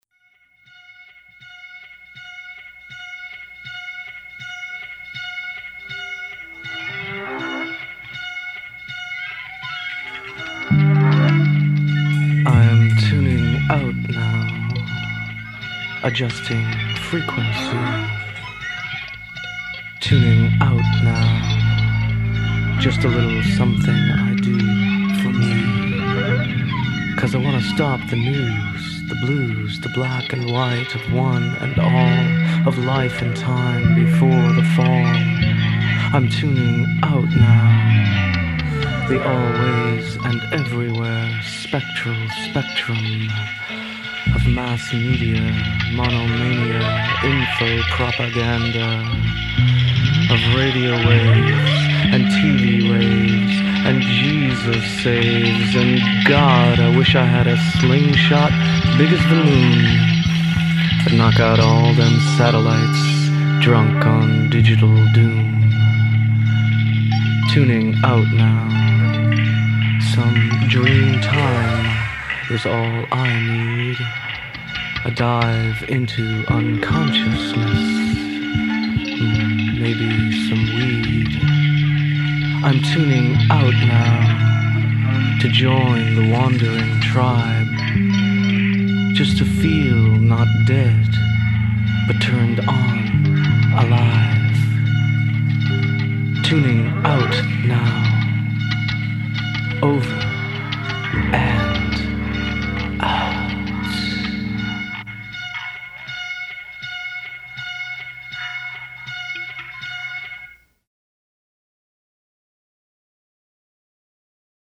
music/spoken word project